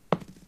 diceThrow4.ogg